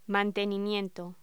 Locución: Mantenimiento
voz